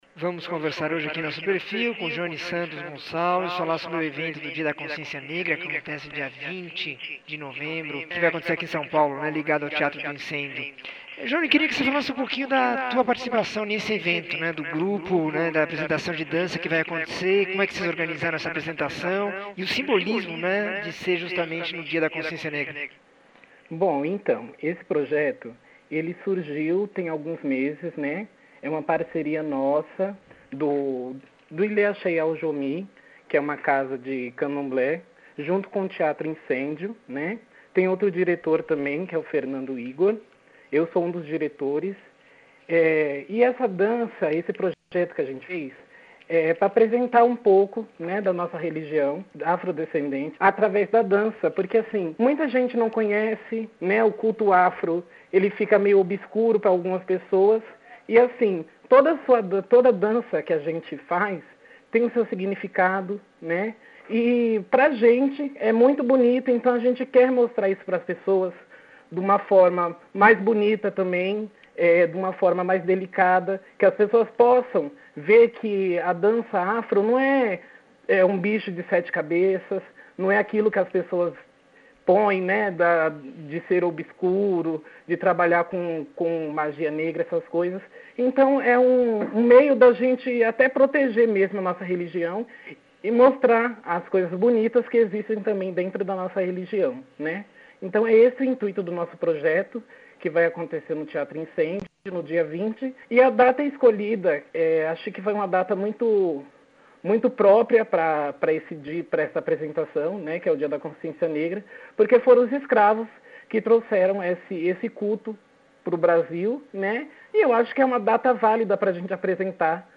Entrevista com um dos diretores da programação do Dia da Consciência Negra no Teatro do Incêndio.